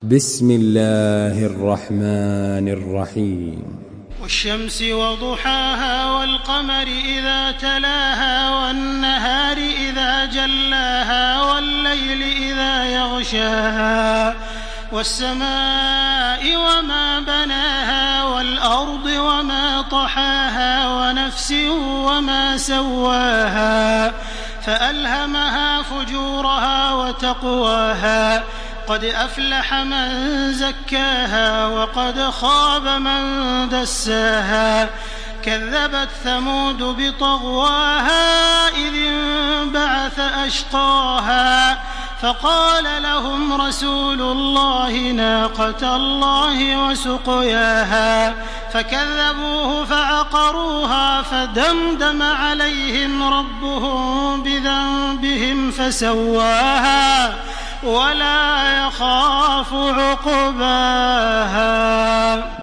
تحميل سورة الشمس بصوت تراويح الحرم المكي 1429
مرتل